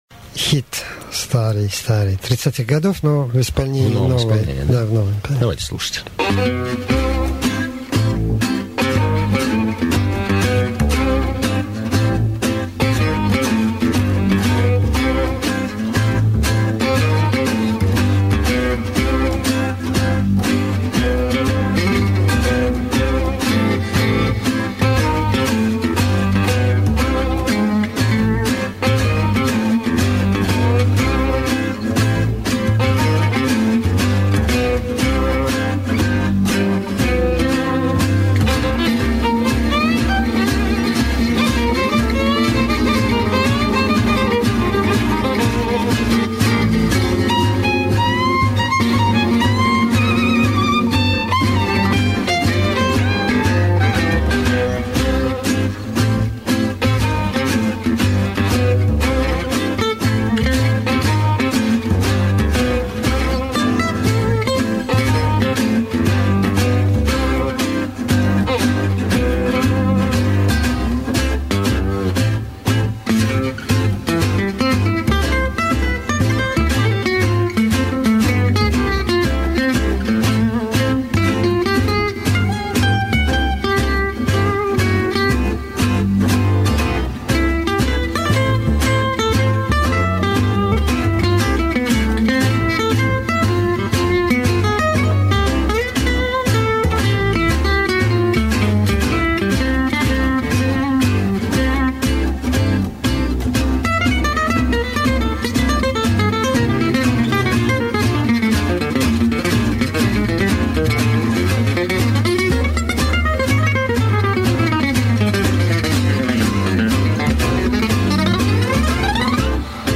Новинки французского джаза.mp3